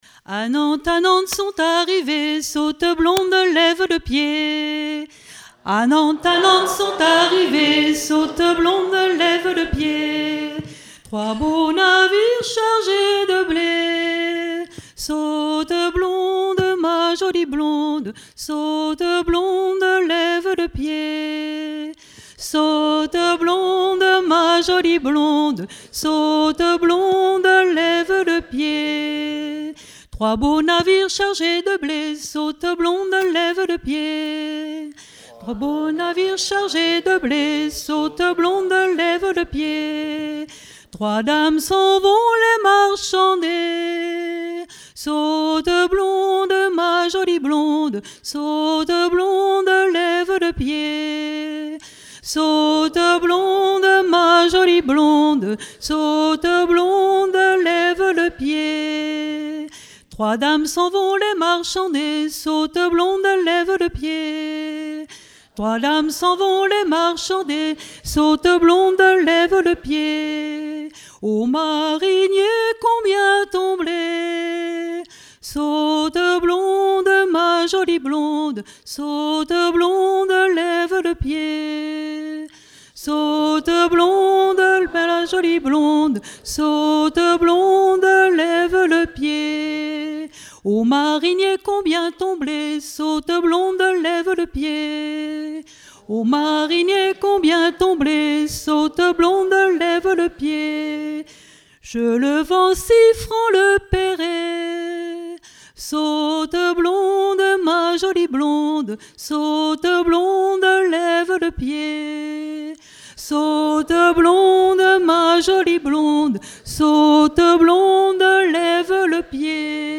Festival du chant traditionnel - 31 chanteurs des cantons de Vendée
Pièce musicale inédite